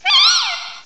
cry_not_amoonguss.aif